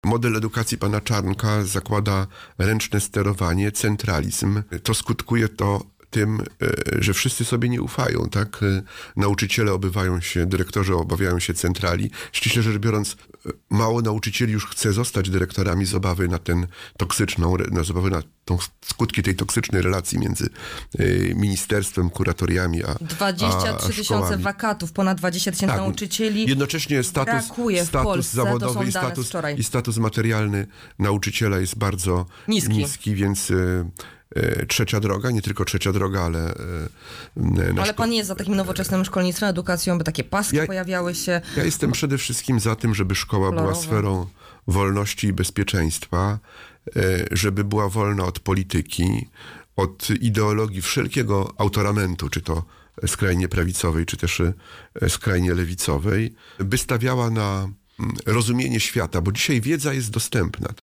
Mam nadzieję, że Polacy na to oszustwo się nie nabiorą, -Marzę o tym, by za jakiś czas mur na granicy białoruskiej przestał istnieć, – W szkołach istnieją toksyczne relacje – mówił w audycji „Poranny Gość” senator K.M Ujazdowski.